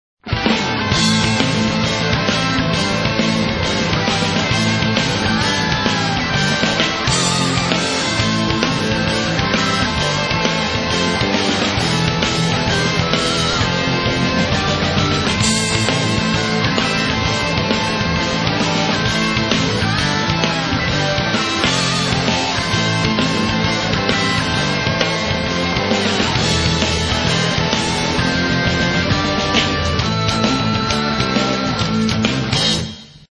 Xtreme / Fusion (Live)